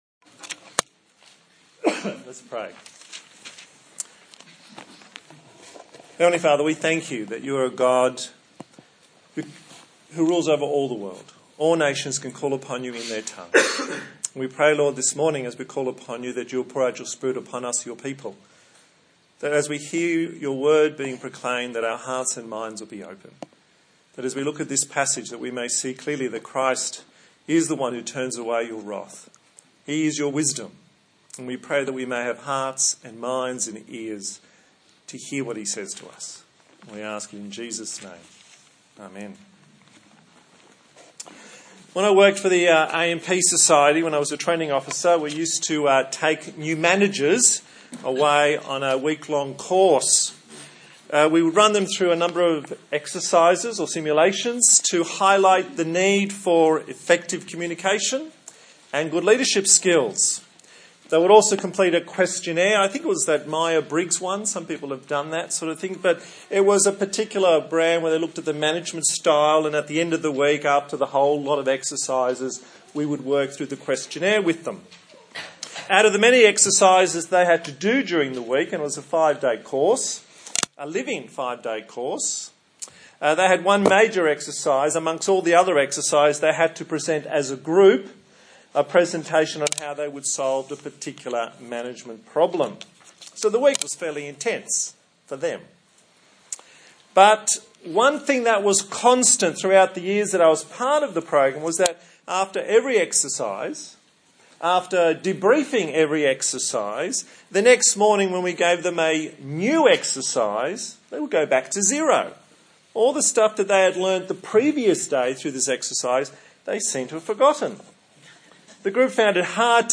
1 Samuel Passage: 1 Samuel 25 Service Type: Sunday Morning